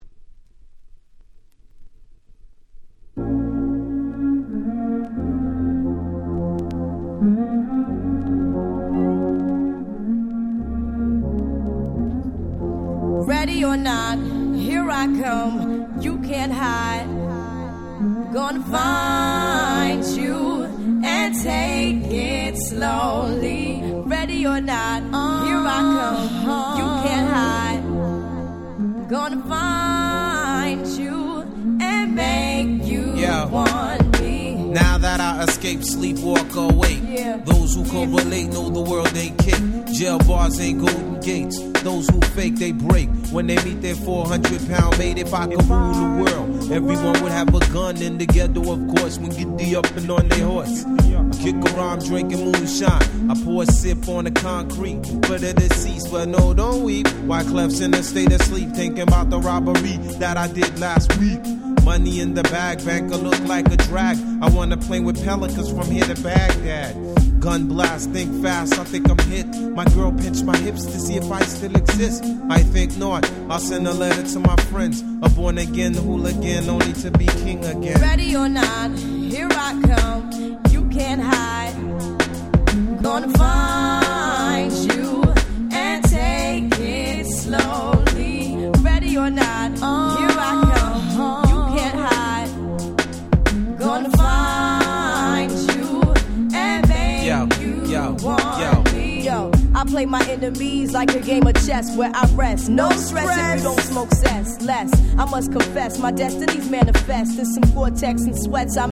96' Super Hit R&B !!